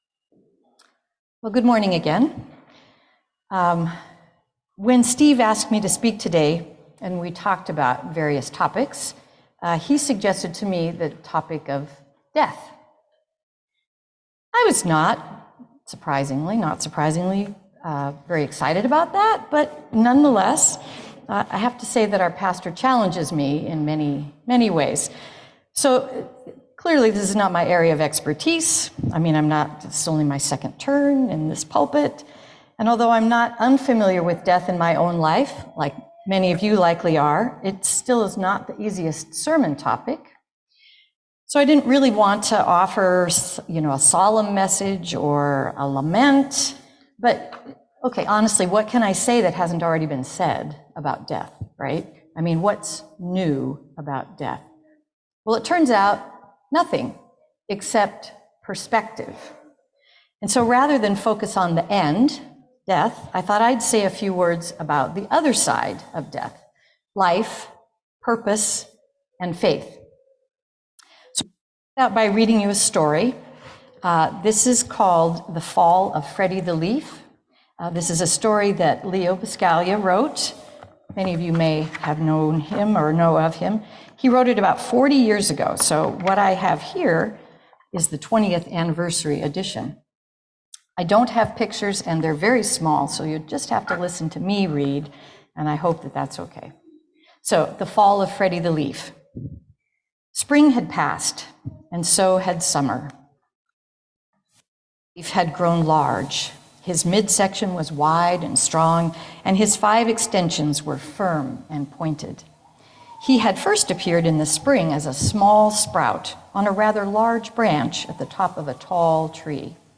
sermon-9-18-22.mp3